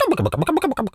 pgs/Assets/Audio/Animal_Impersonations/turkey_ostrich_gobble_09.wav at master
turkey_ostrich_gobble_09.wav